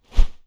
Close Combat Swing Sound 14.wav